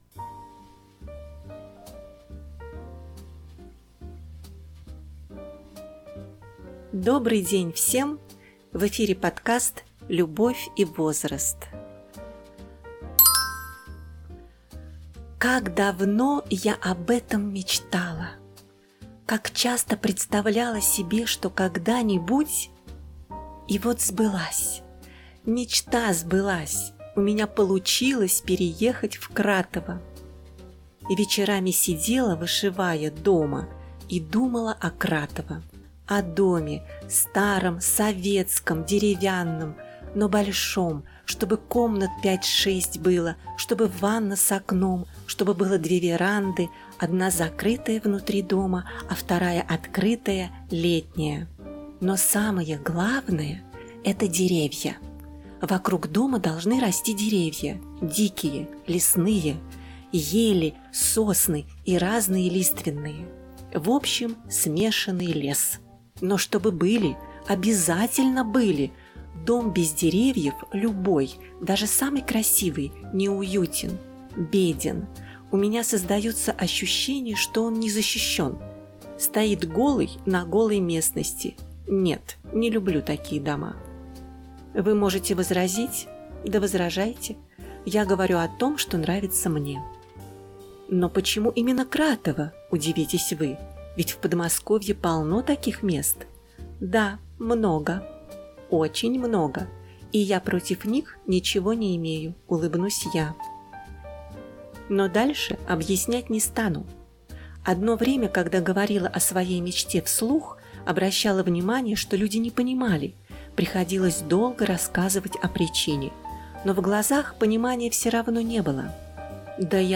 1 часть – рассказ (начало) о сбывшейся мечте от первого лица